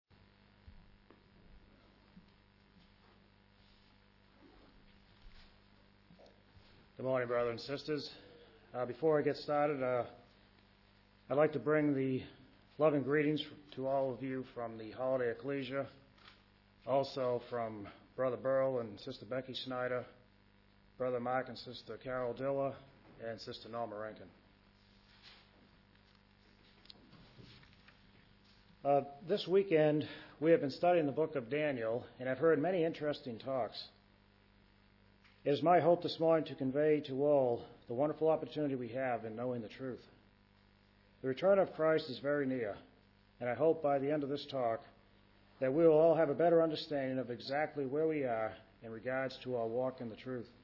Wales March Gathering - March 17th to 21st, 2006